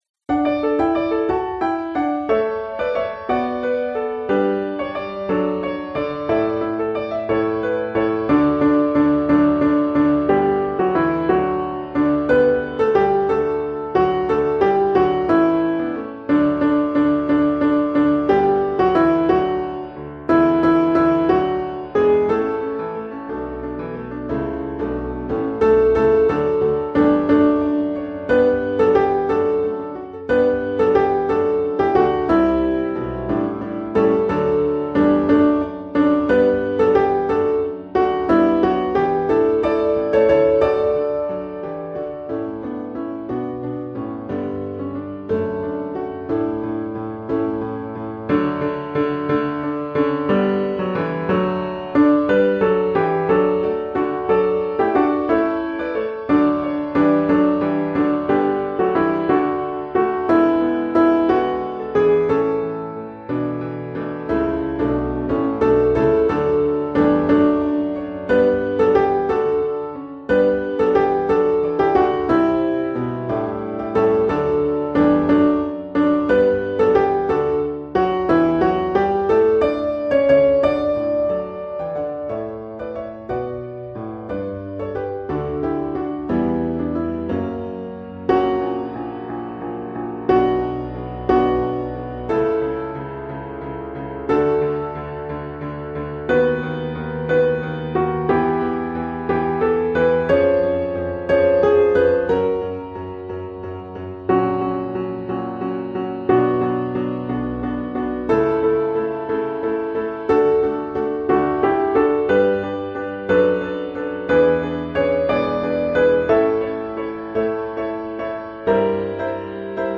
Joy Overflowing – Soprano
Joy-Overflowing-Soprano.mp3